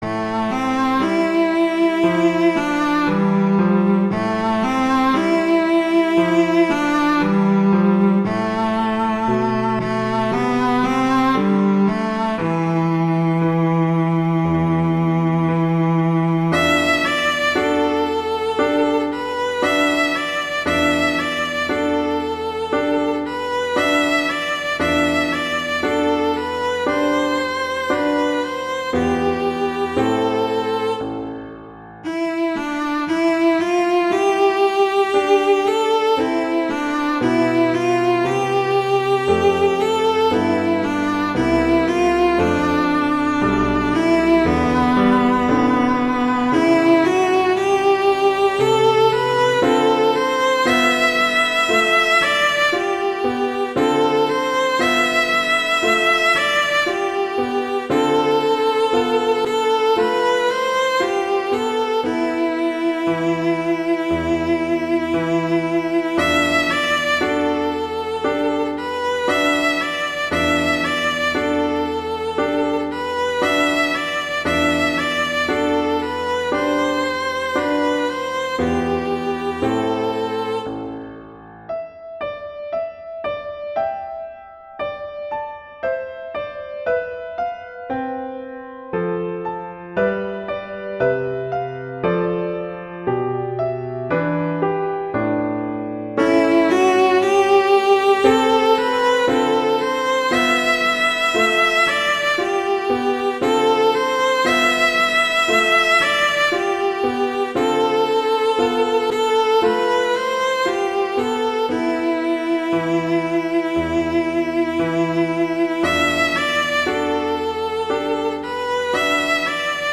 classical, french
A minor
♩=60 BPM